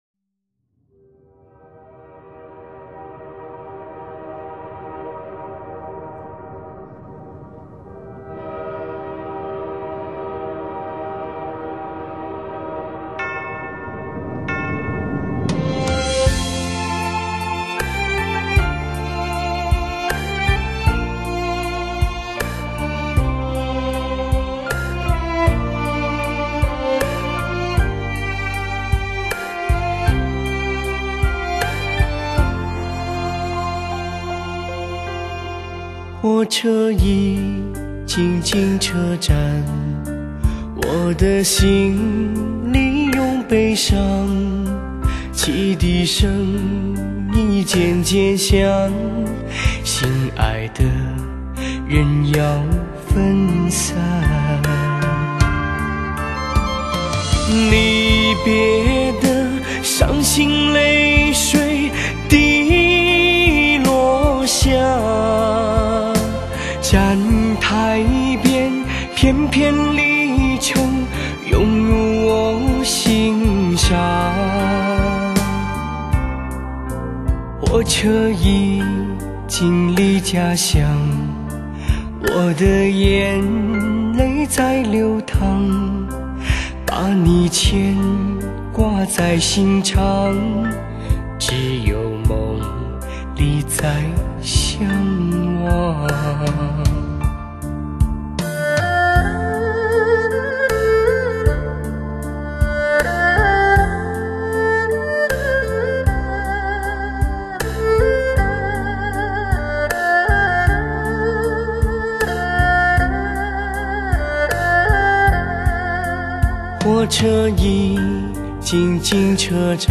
超高品质录音跨越发烧技术概念，